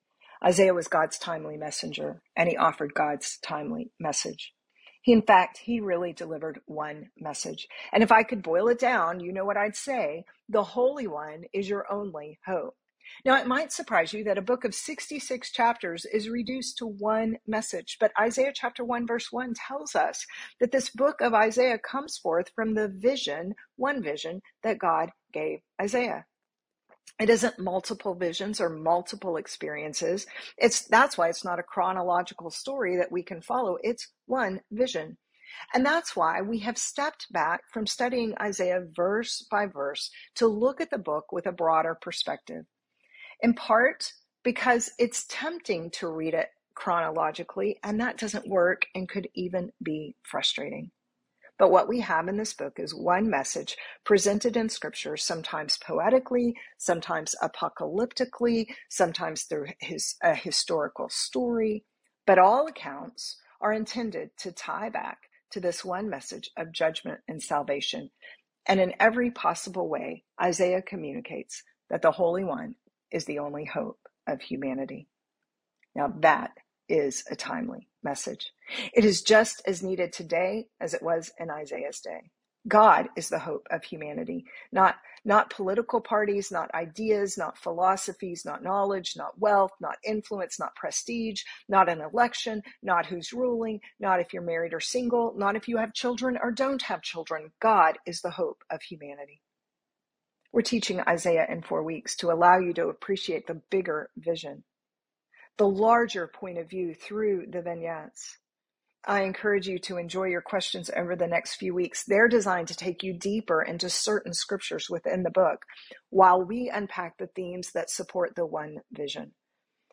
This excerpt is from a pre-recorded lecture